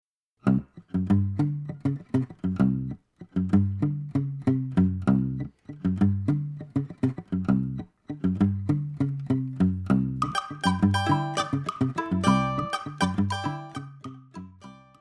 Choro brasileiro